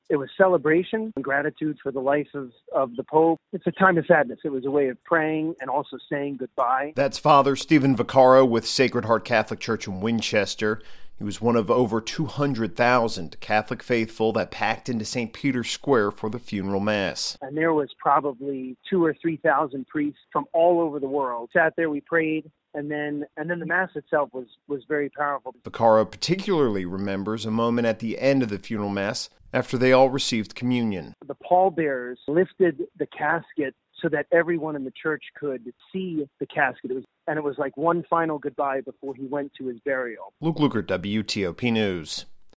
spoke with a local priest who attended the service to mourn with the Catholic faithful